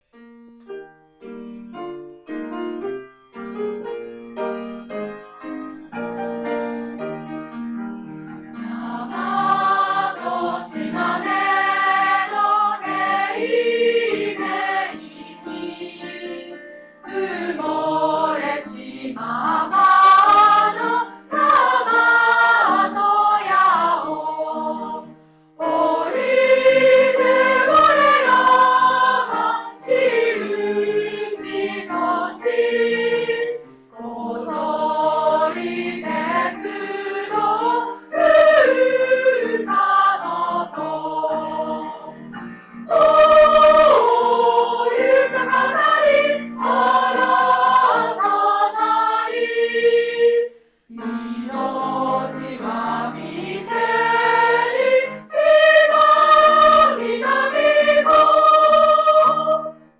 下の音符をクリックすると、吹奏楽部の皆さんによる校歌の合唱を聞くことができます。